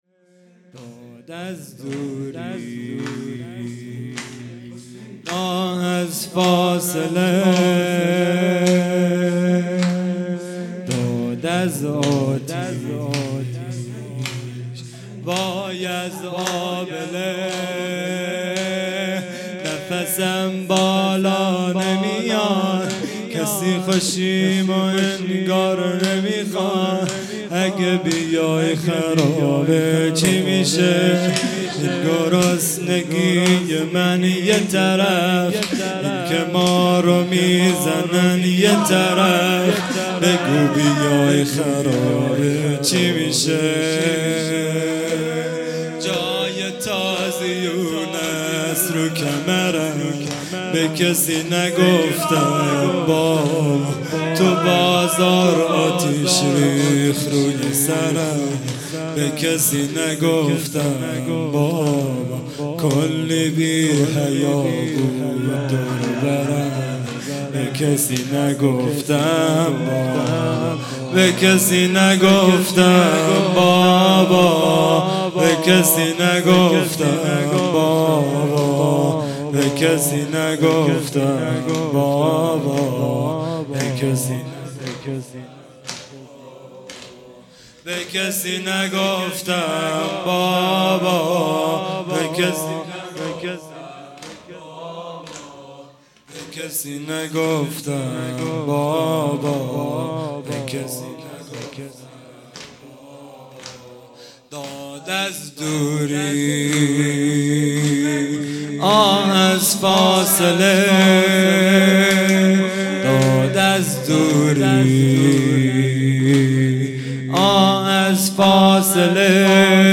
خیمه گاه - هیئت بچه های فاطمه (س) - زمینه | داد از دوری آه از فاصله | پنج شنبه ۱۸ شهریور ۱۴۰۰